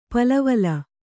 ♪ palavala